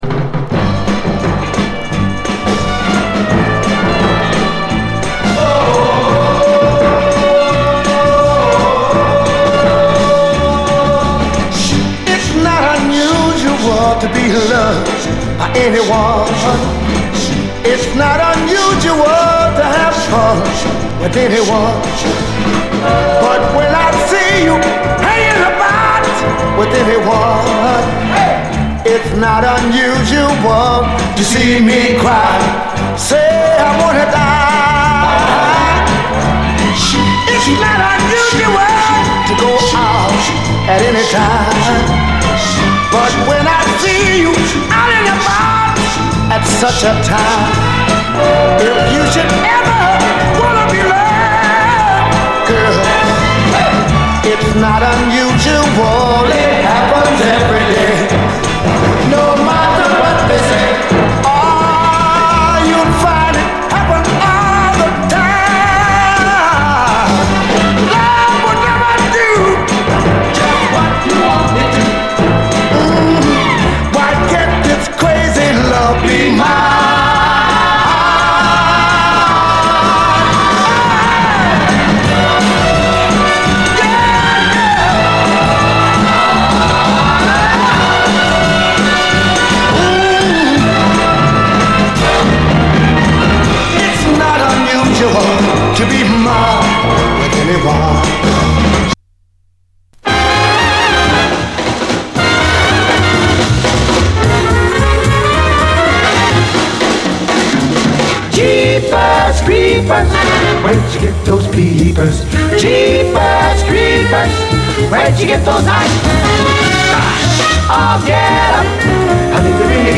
SOUL, 60's SOUL
エレガントなシカゴ・ソウルの神髄！
ソウル〜スウィング〜R&Bを境目なく洒脱にこなし、エレガントなシカゴ・ソウルの神髄を見せつける名盤です！
キラー・ドリーミー・ソウル！